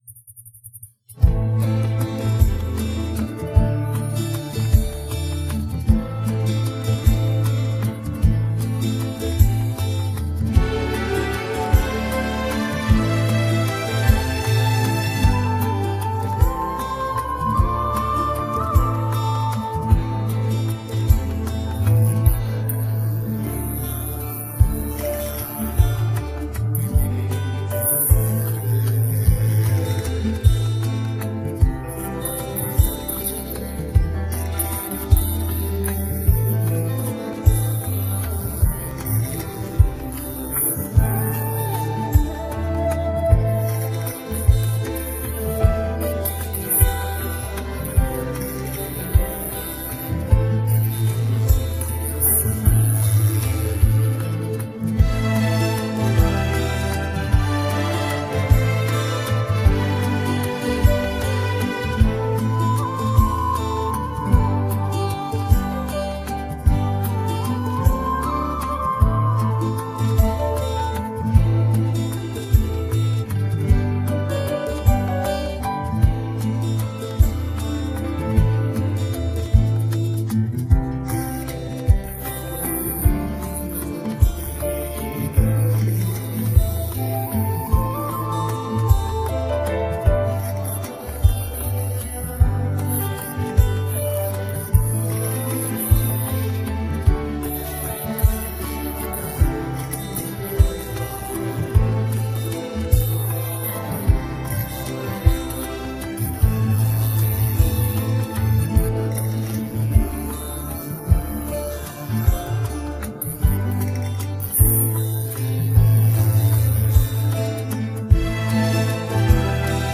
Play Karaoke & Sing with Us
Vocals